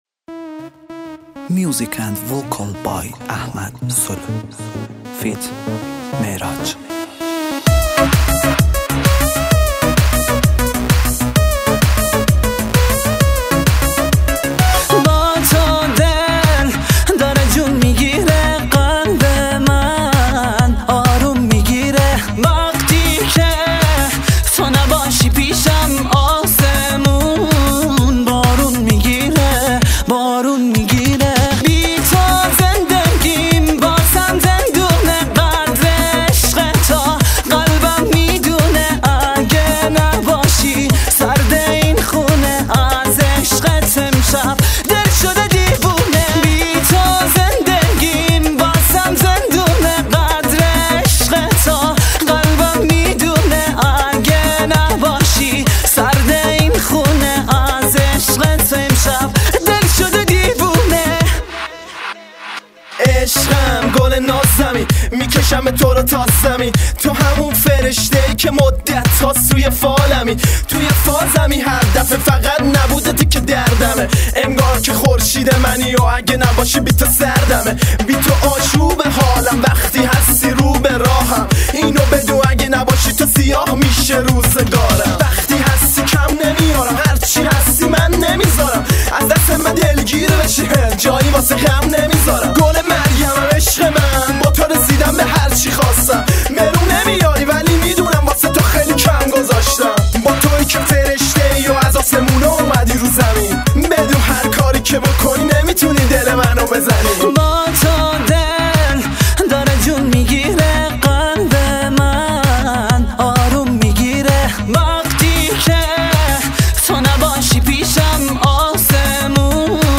تک آهنگ